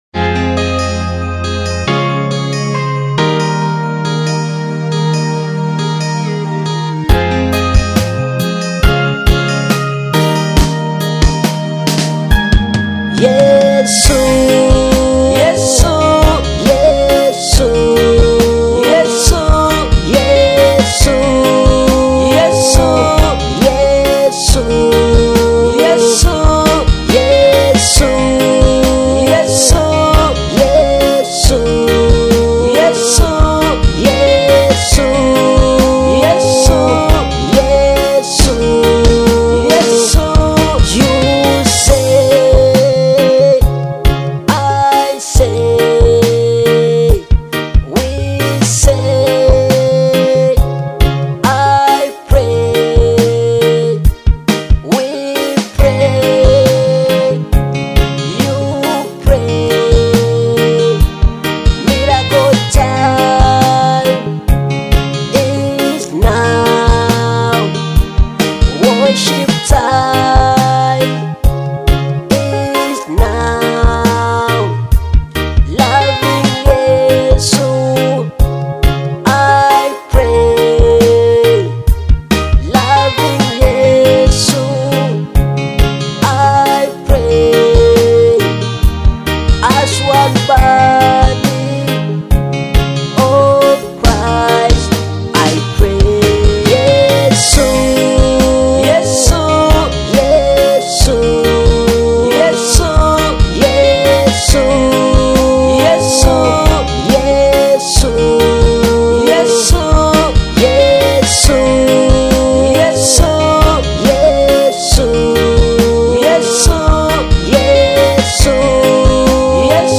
a moving gospel song praising Jesus with powerful vocals
and uplifting spiritual energy.